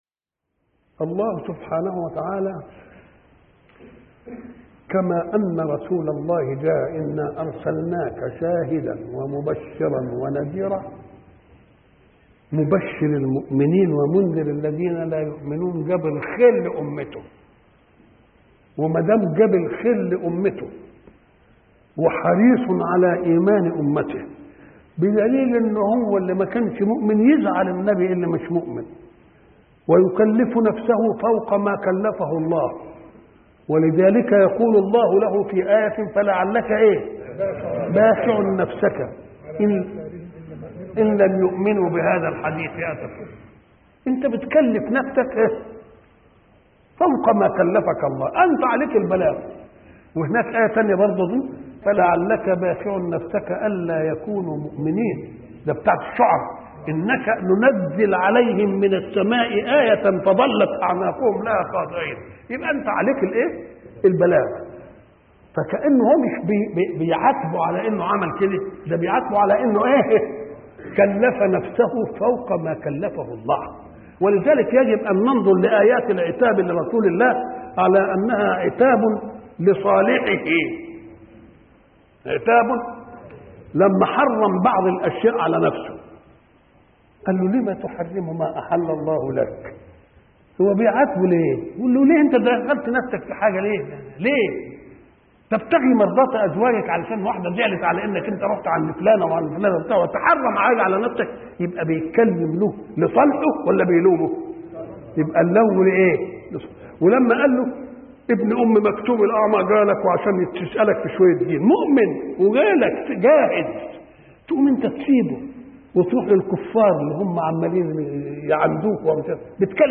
شبكة المعرفة الإسلامية | الدروس | حرص النبي على أمته |محمد متولي الشعراوي
محمد متولي الشعراوي